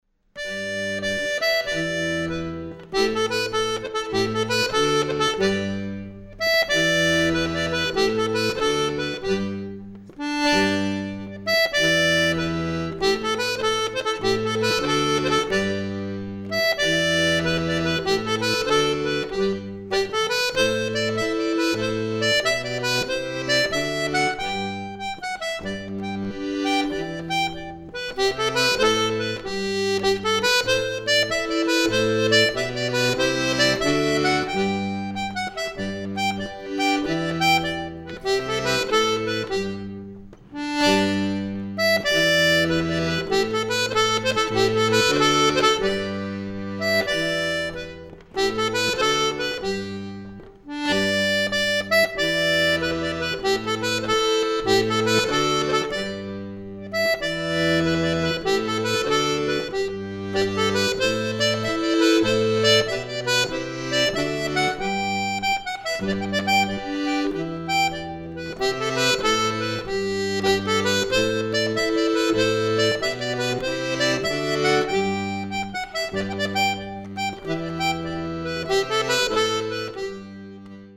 Accordéon diatonique
Une jolie marche apprise auprès de